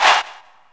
ZayChant Ha1.wav